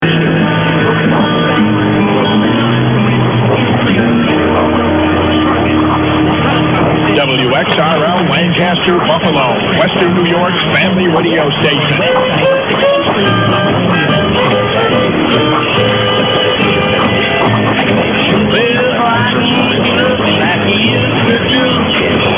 I meant to include this small clip the other week of WXRL during a good peak. This station is quite rare here, with common WOOD often dominant.